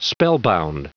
Prononciation du mot spellbound en anglais (fichier audio)
Prononciation du mot : spellbound